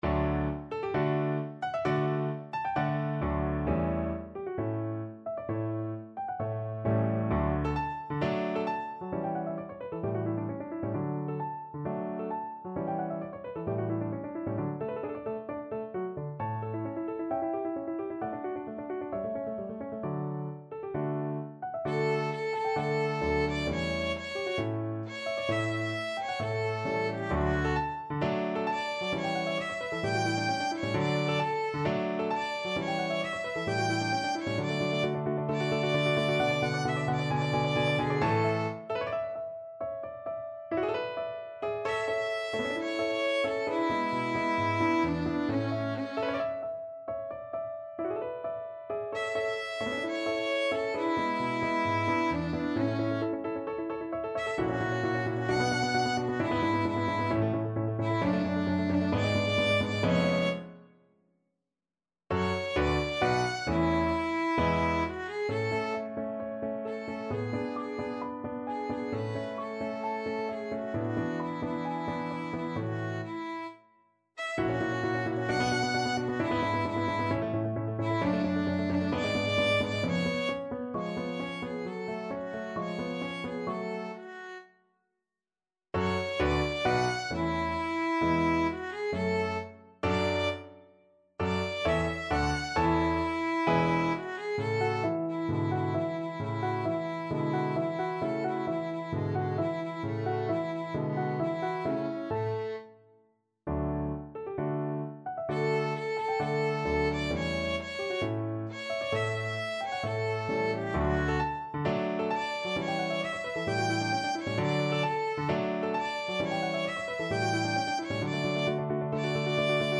Classical Mozart, Wolfgang Amadeus Ah! chi mi dice mai from Don Giovanni Violin version
Violin
D major (Sounding Pitch) (View more D major Music for Violin )
~ = 132 Allegro (View more music marked Allegro)
4/4 (View more 4/4 Music)
Classical (View more Classical Violin Music)